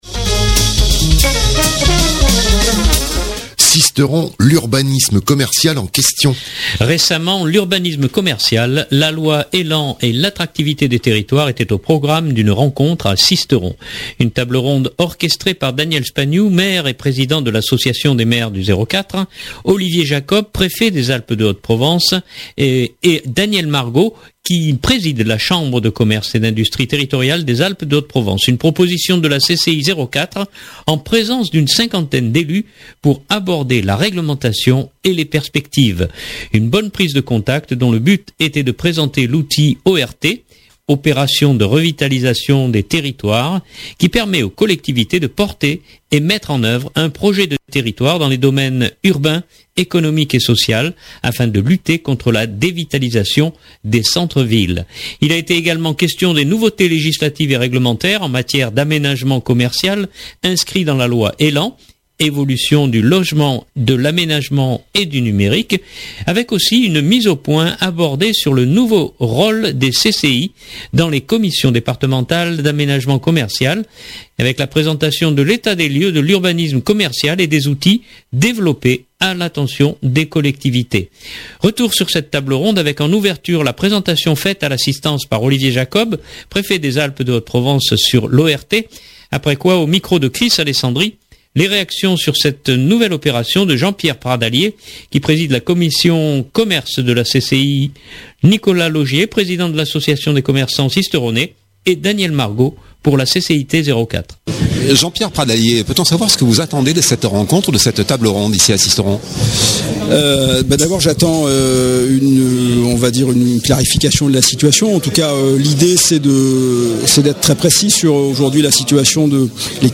Retour sur cette table ronde, avec en ouverture la présentation faite à l’assistance, par Olivier Jacob, Préfet des Alpes de haute Provence sur l’ORT